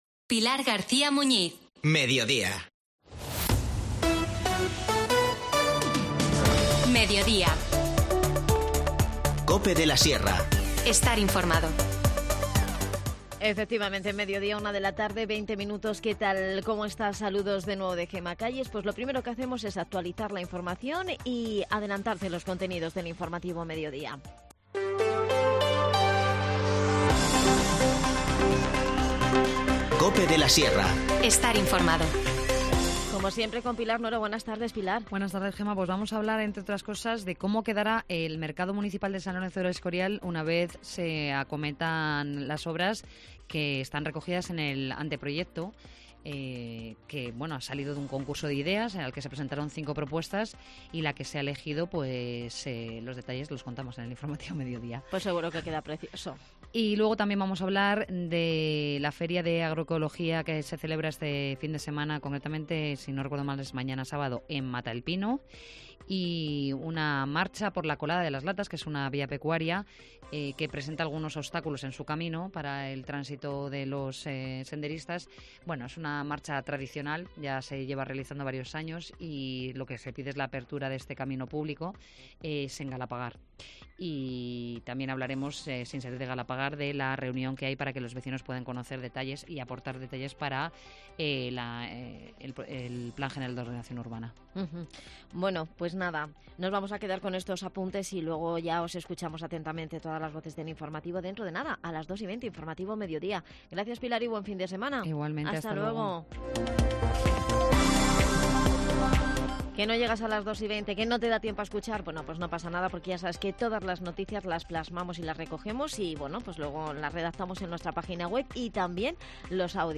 INFORMACIÓN LOCAL